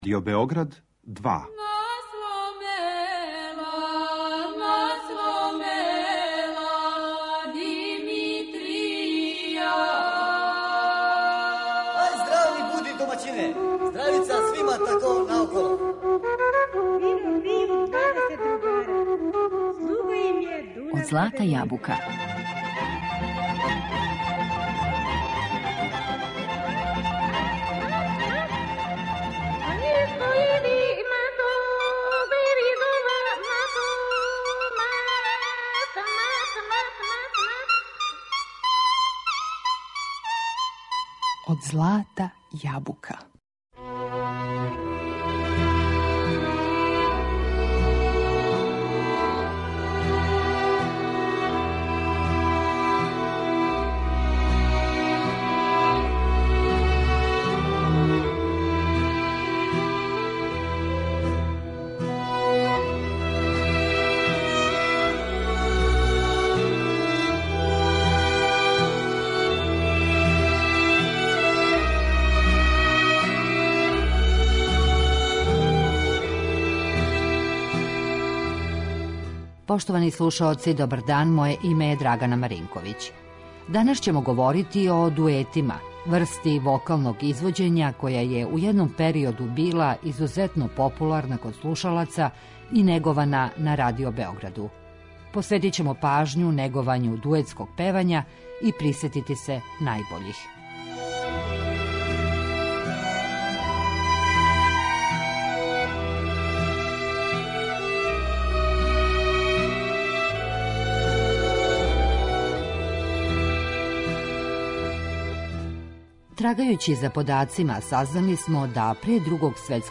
Данашњу емисију посветили смо историји дуетског певања на Радио Београду.